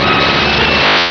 Cri de Sulfura dans Pokémon Rubis et Saphir.